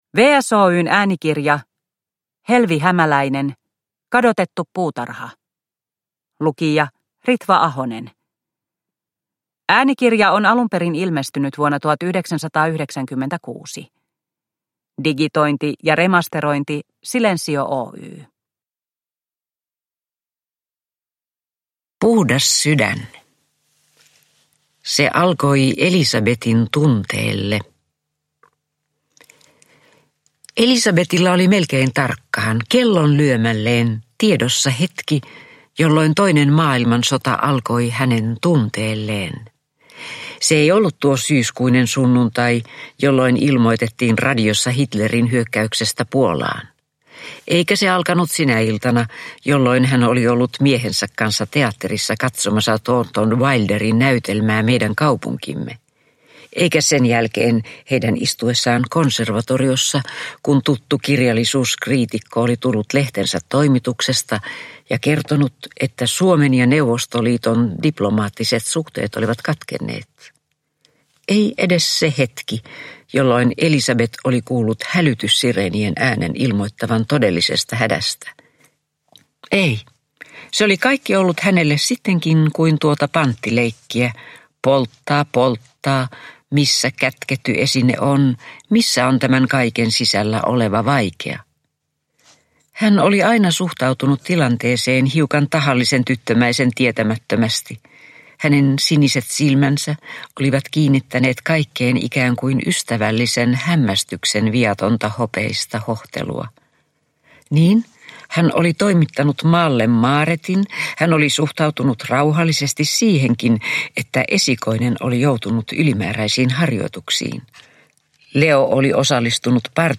Kadotettu puutarha – Ljudbok – Laddas ner
Äänikirja on valmistunut vuonna 1996.